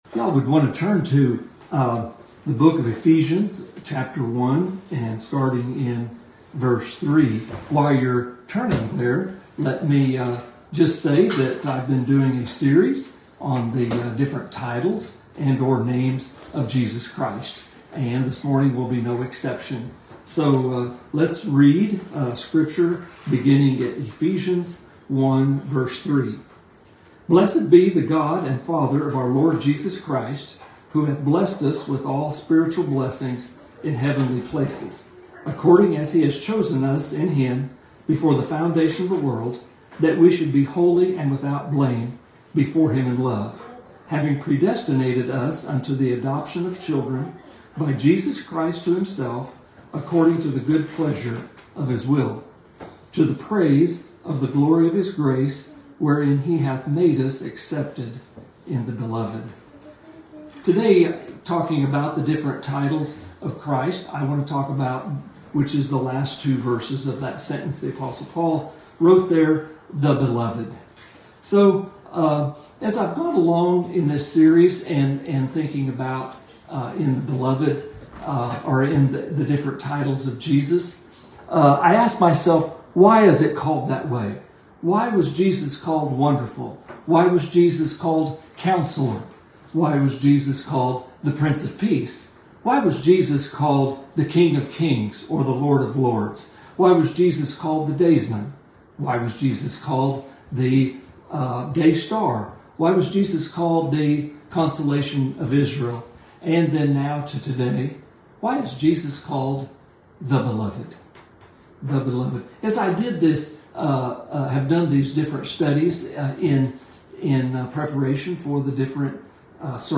Due to the ice storm, church was held remotely today.